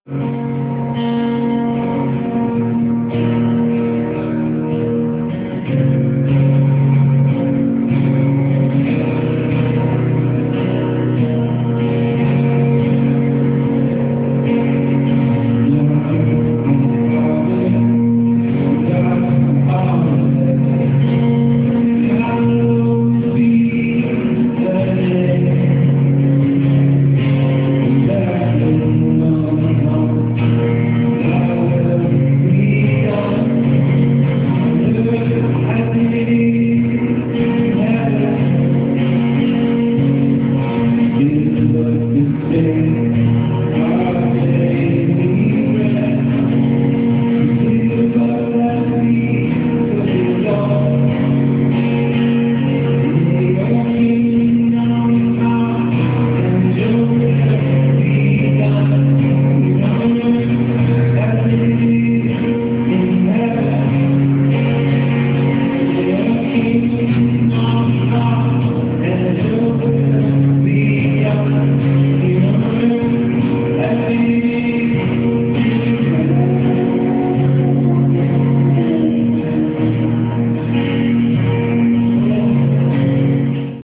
Saturday night and Sunday Morning worship service  January 24-25, 2026